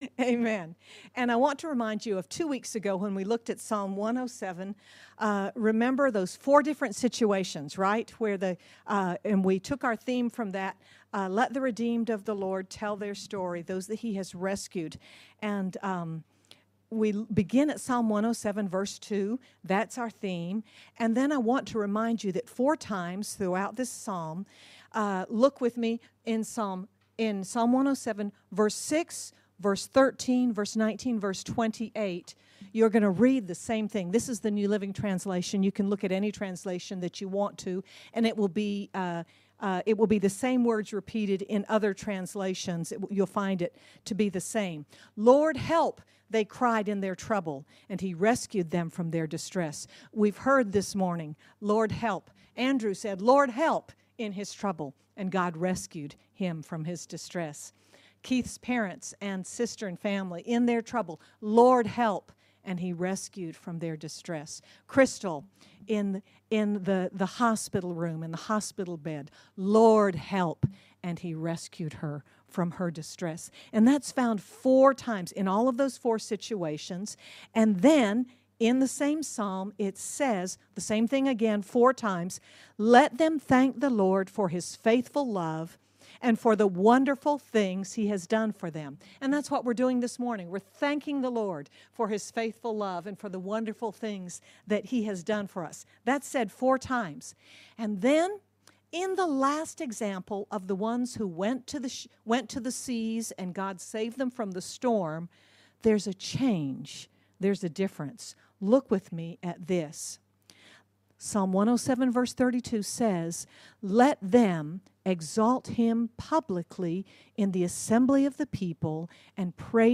Oct 04, 2022 Let the Redeemed tell their Story—II MP3 SUBSCRIBE on iTunes(Podcast) Notes Discussion We conclude our anniversary celebration with four reasons to tell our story and more testimonies of God’s goodness! Sermon by